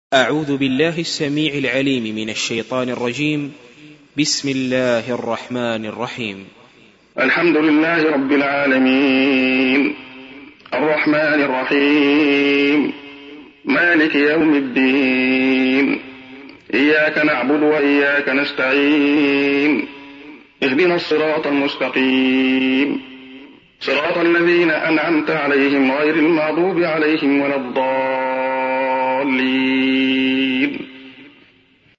سُورَةُ الفَاتِحَةِ بصوت الشيخ عبدالله الخياط